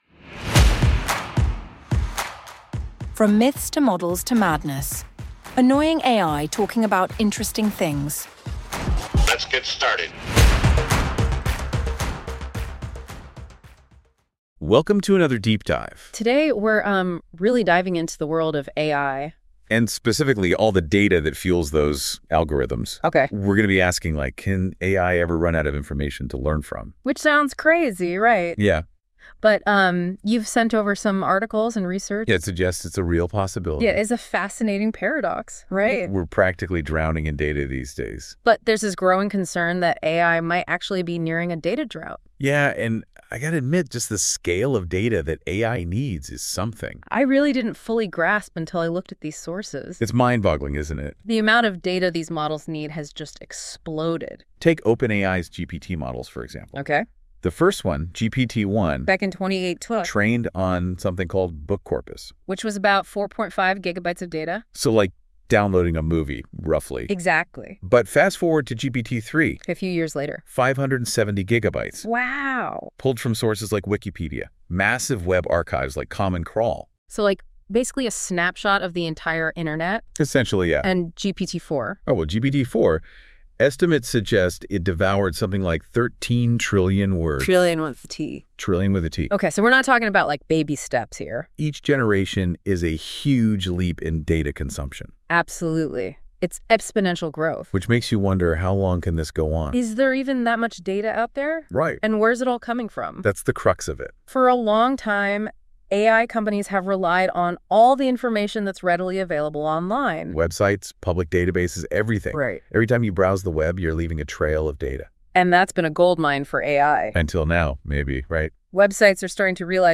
From Myths To Models To Madness: Annoying AI Talking about Interesting Things